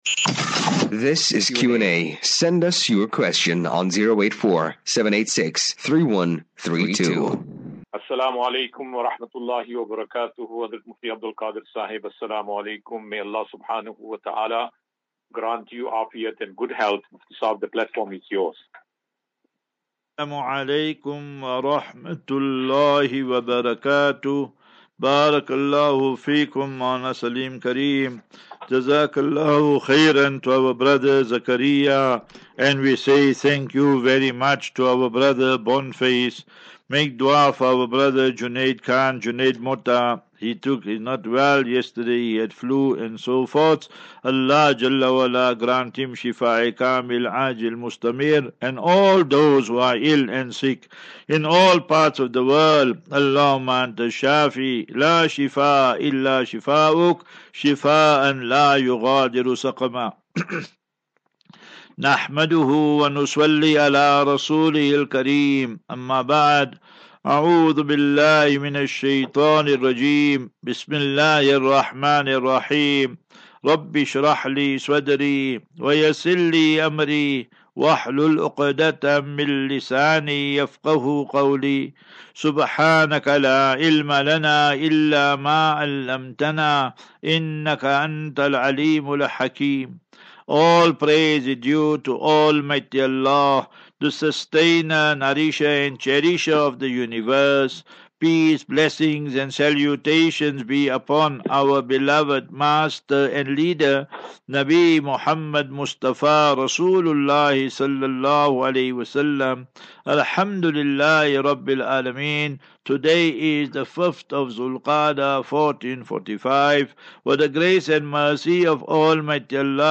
Daily Naseeha.